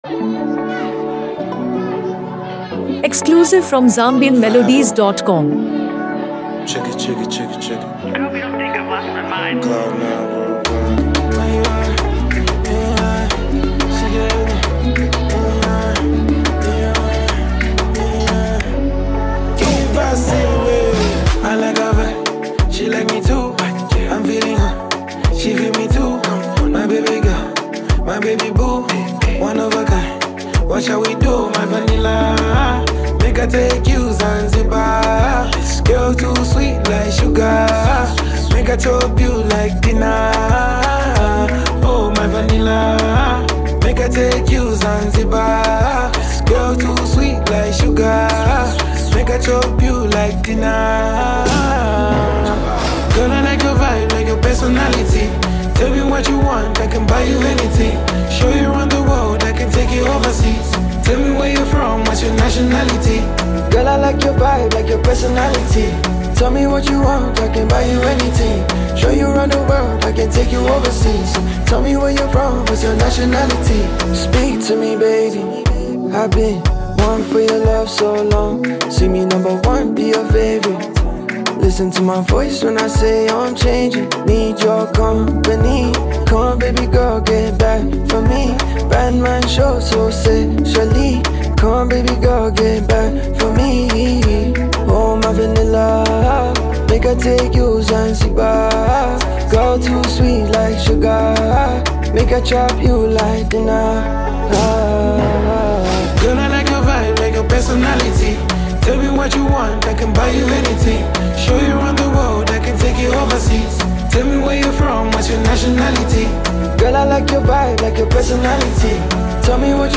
a bold and infectious track that celebrates identity
smooth, soulful vocals
rich tone and emotional presence